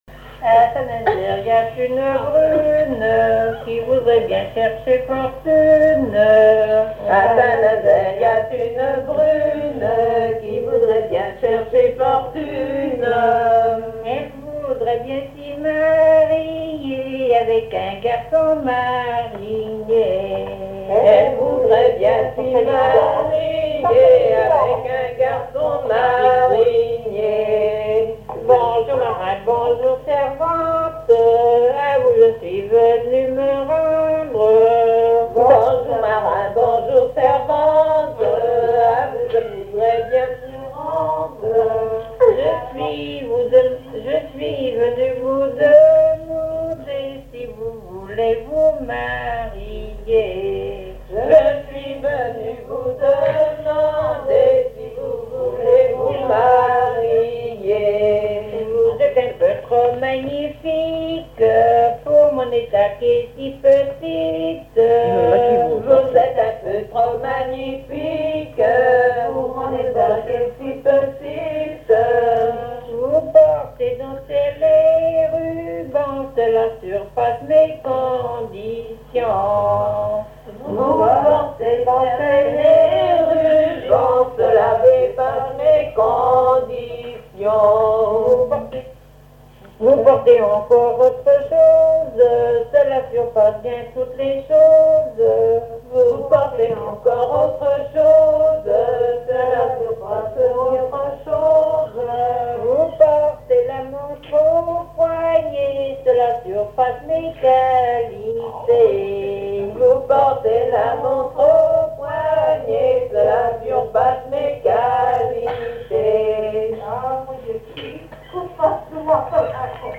danse : ronde à la mode de l'Epine
collecte en Vendée
chansons traditionnelles et commentaires